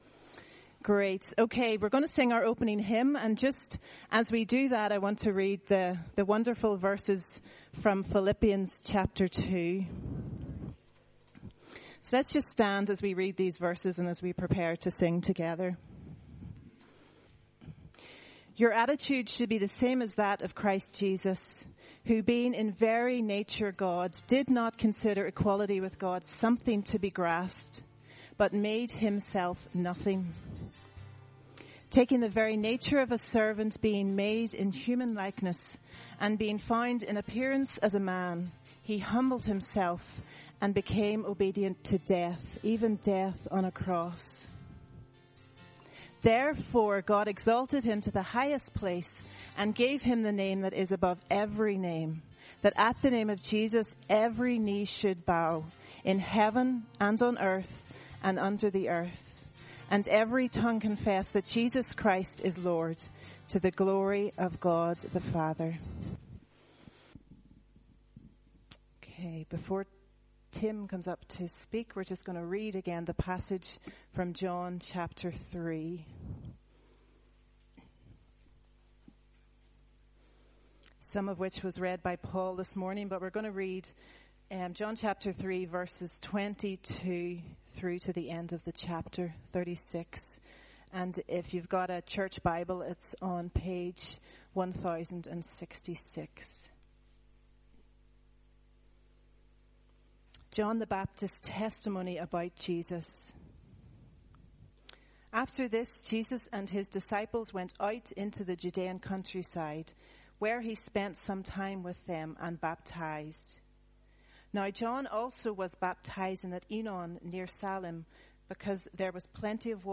Sunday Service
He must increase, I must decrease Sermon